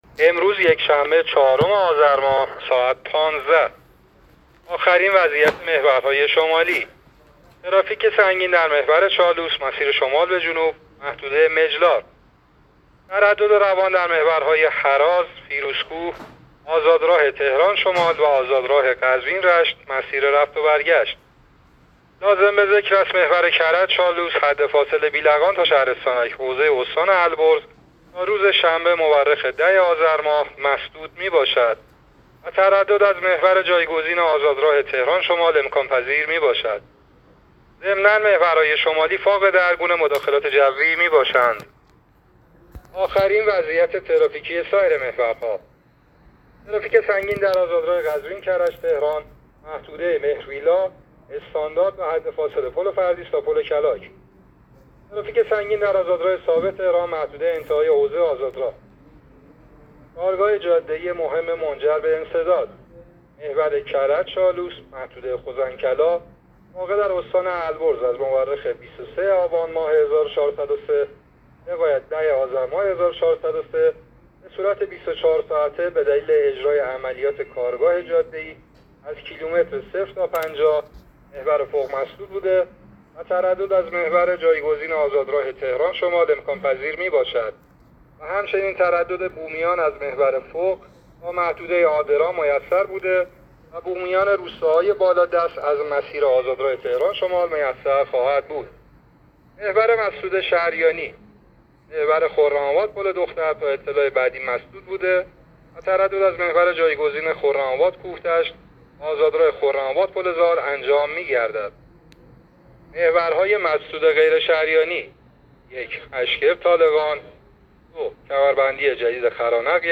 گزارش رادیو اینترنتی از آخرین وضعیت ترافیکی جاده‌ها تا ساعت ۱۵ چهارم آذر؛